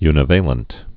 (ynĭ-vālənt)